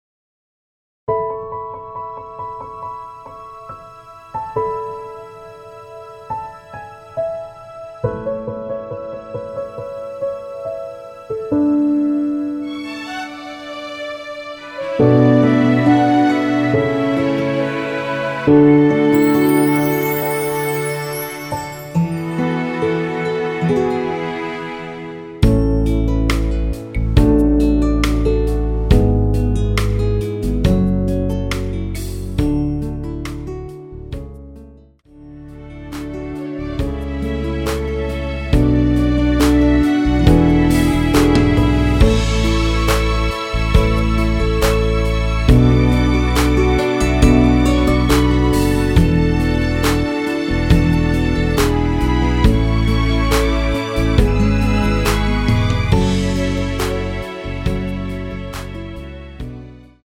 원키에서(-7)내린 MR입니다.
앞부분30초, 뒷부분30초씩 편집해서 올려 드리고 있습니다.
중간에 음이 끈어지고 다시 나오는 이유는